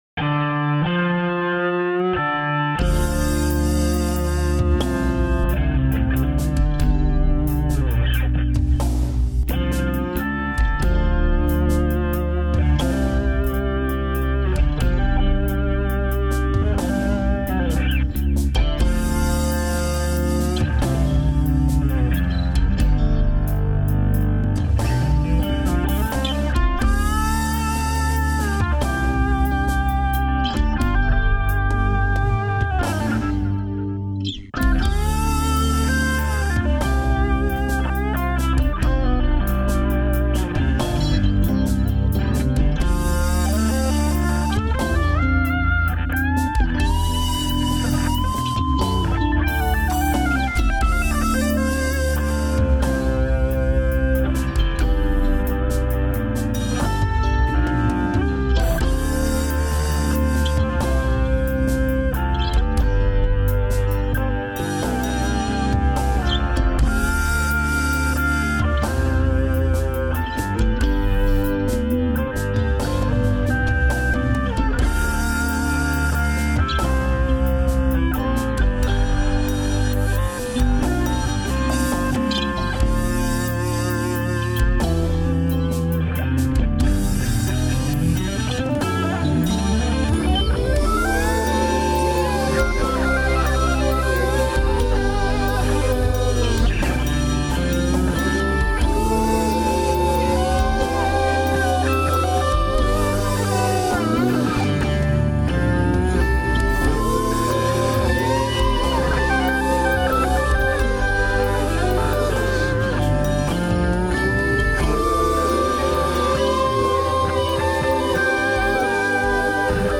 Guitares, basses, vocaux, drums